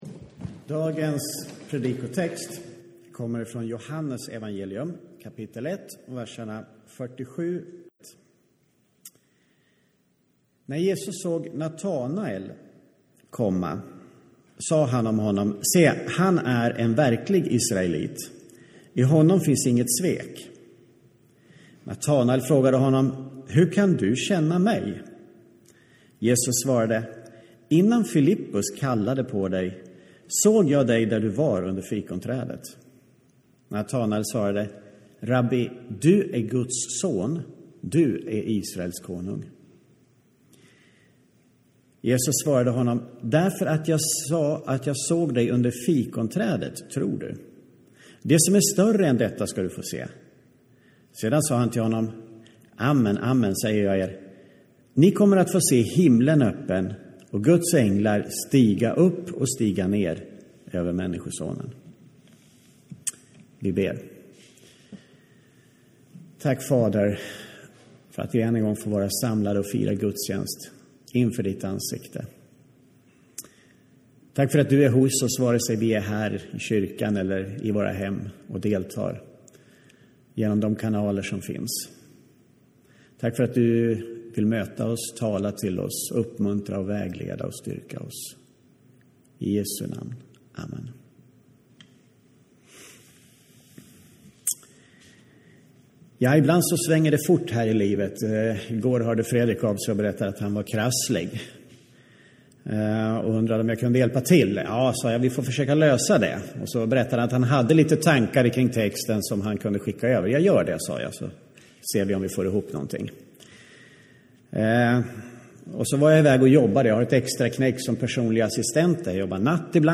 podcast-predikan-4-okt-2020